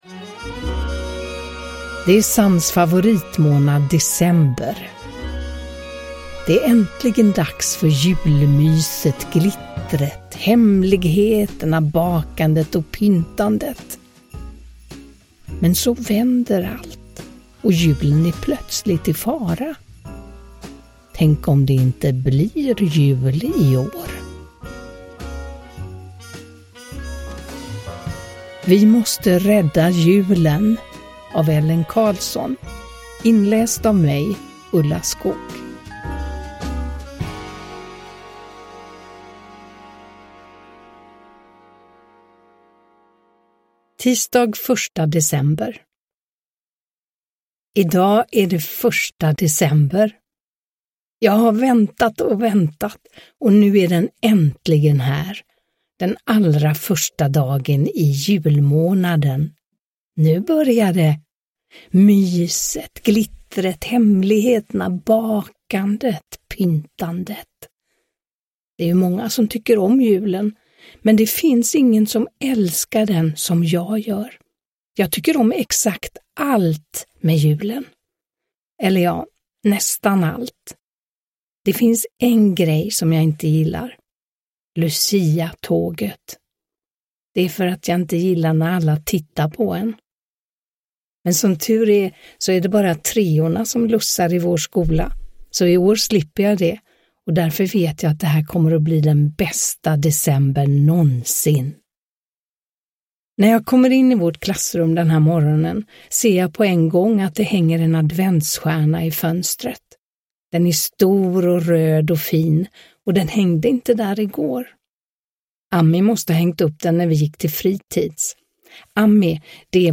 Vi måste rädda julen! – Ljudbok – Laddas ner
Uppläsare: Ulla Skoog